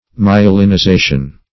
Meaning of myelinization. myelinization synonyms, pronunciation, spelling and more from Free Dictionary.